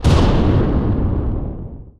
EXPLOSION_Medium_Plate_Reverb_stereo.wav